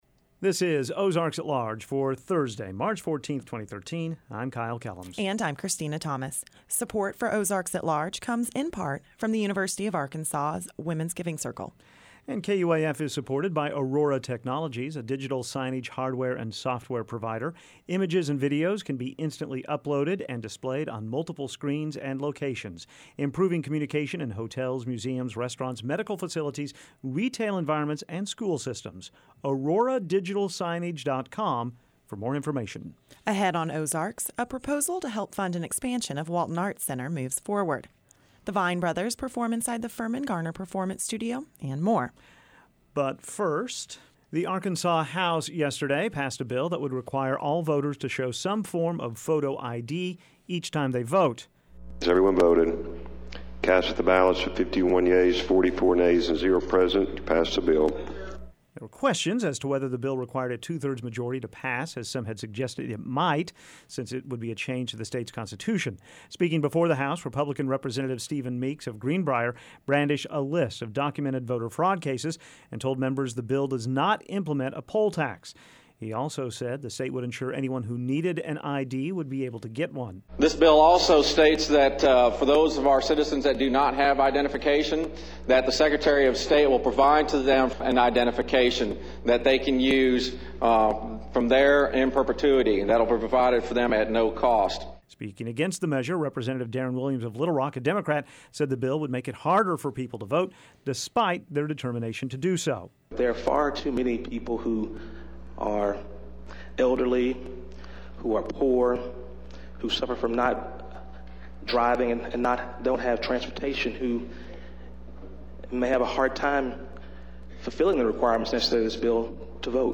Ahead on Ozarks, highlights from a lecture given by David Pryor last night in downtown Fayetteville. Also, the region's population prepares to reach the half-million mark.